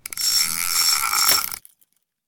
fishreelin.ogg